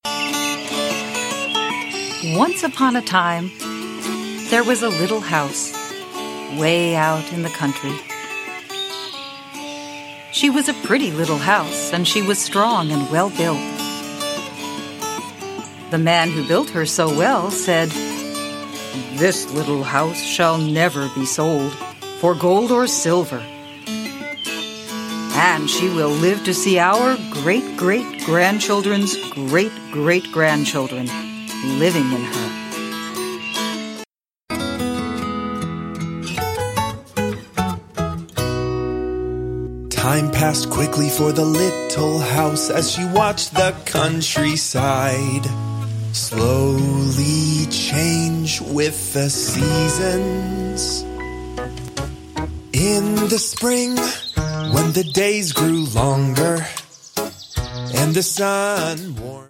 CDのStory Songのトラックの「歌」は一部のページで、ノーマルスピードの朗読がメインです。